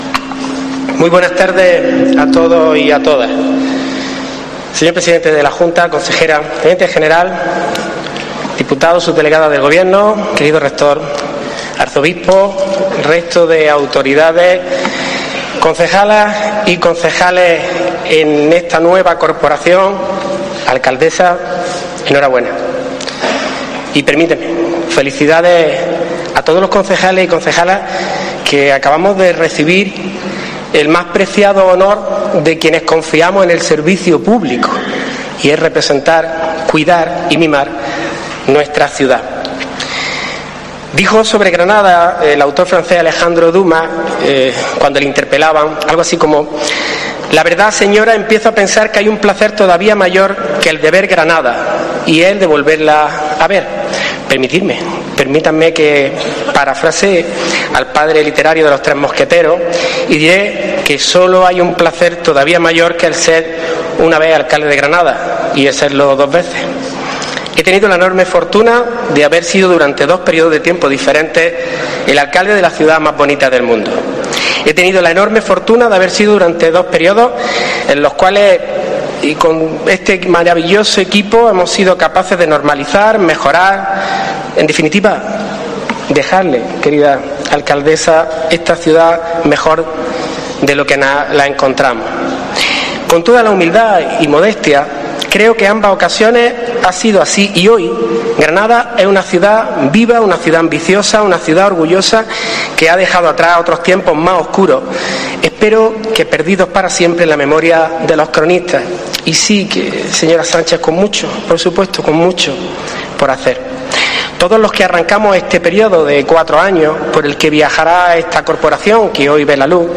Ha sido en la primera intervención del ya exalcalde de Granada tras tomar posesión del cargo de regidora su sucesora, Marifrán Carazo (PP), arropada por el presidente de la Junta, Juanma Moreno, la sesión constitutiva de la corporación local de Granada, en la tarde de este sábado en el Patio del Ayuntamiento de la ciudad de la Alhambra.